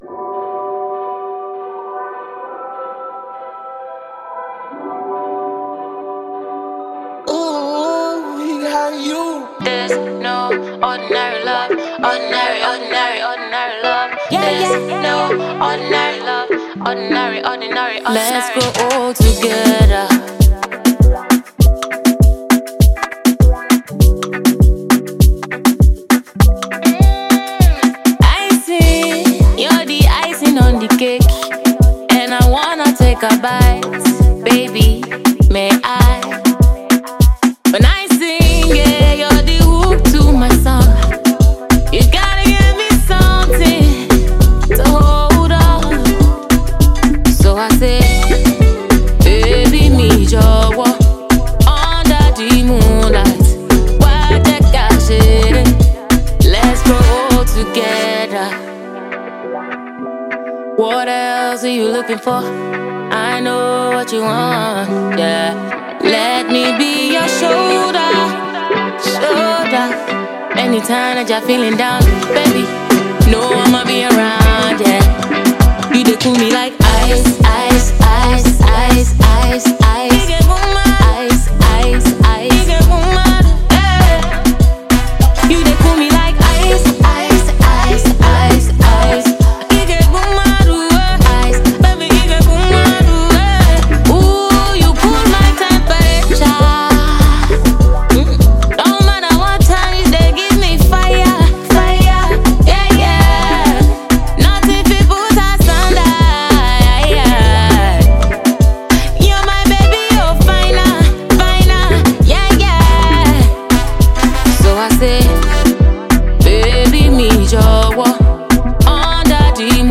Nigerian Afropop singer and songwriter